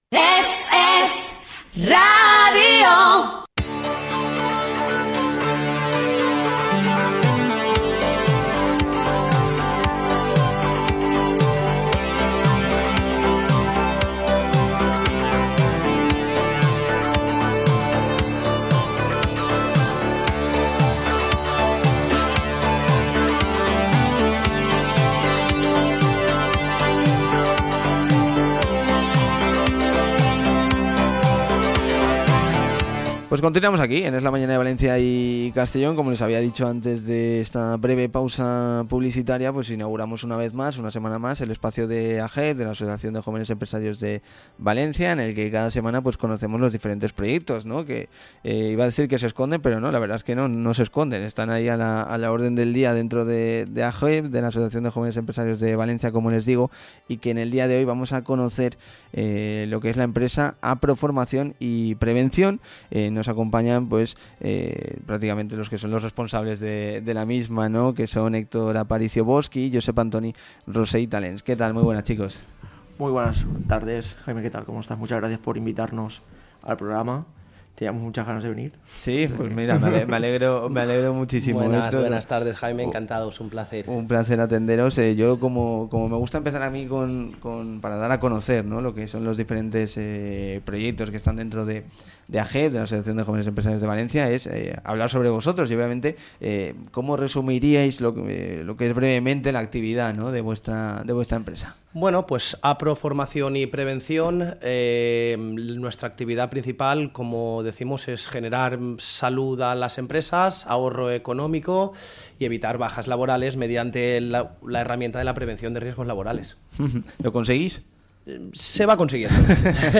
Entrevista completa: